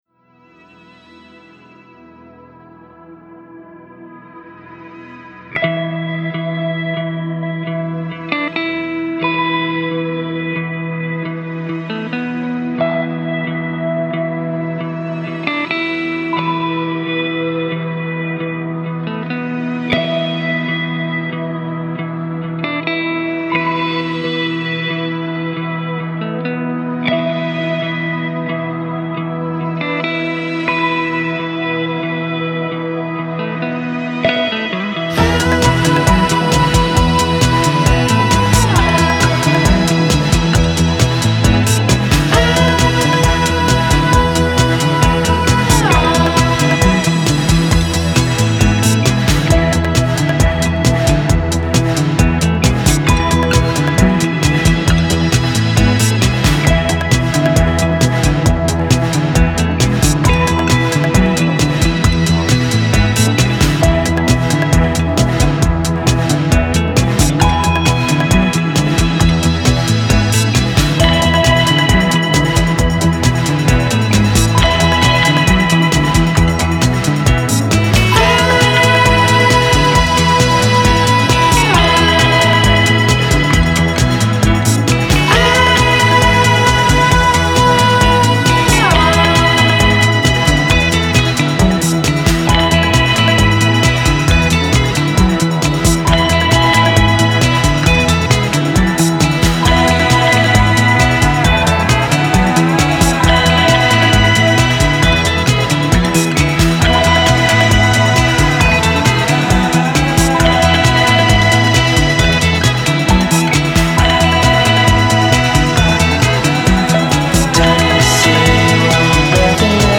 Atmospheric intro building to uptempo driving groove.